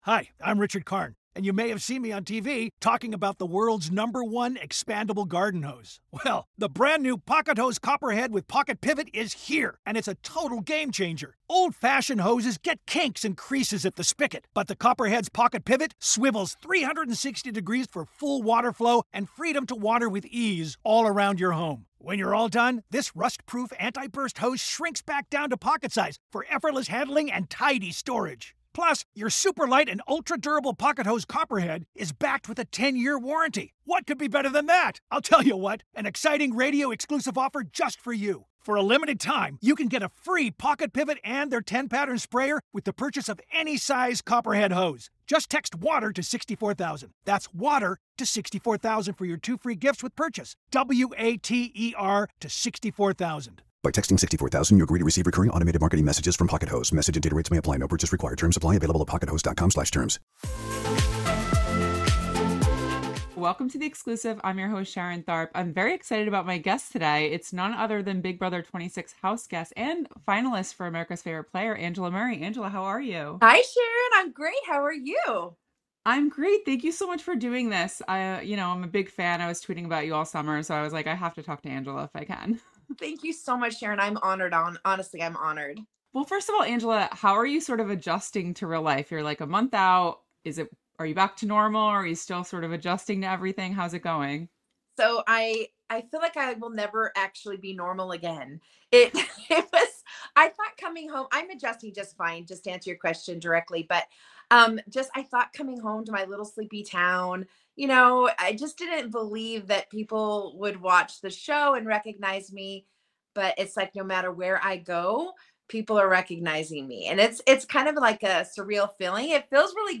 Big Brother 26 Post-Season Interview